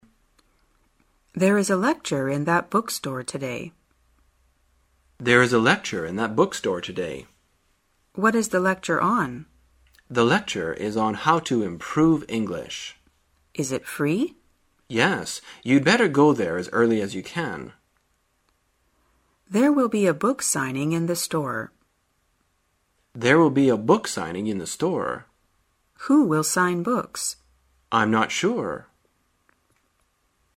在线英语听力室生活口语天天说 第34期:怎样谈论书店的活动的听力文件下载,《生活口语天天说》栏目将日常生活中最常用到的口语句型进行收集和重点讲解。真人发音配字幕帮助英语爱好者们练习听力并进行口语跟读。